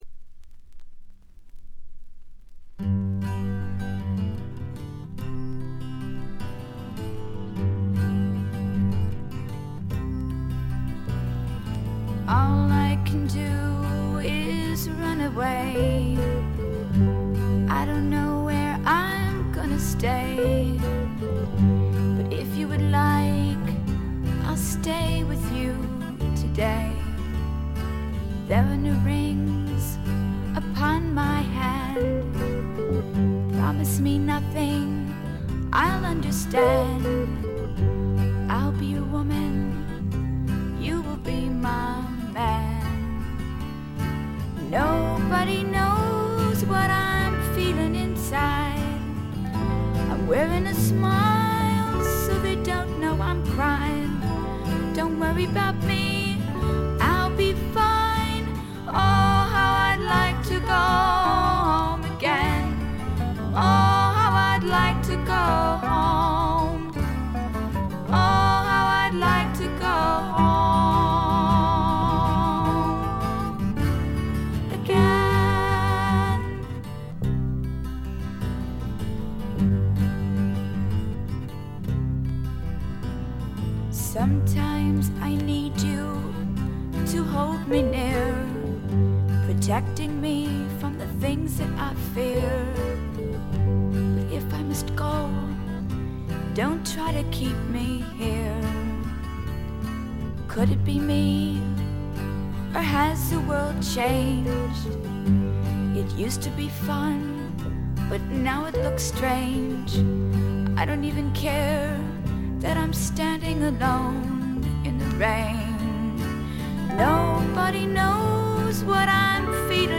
全曲自作の素晴らしい楽曲、清楚な歌声、美しいアコギの音色、60年代気分を残しているバックの演奏、たなびくフルートの音色。
試聴曲は現品からの取り込み音源です。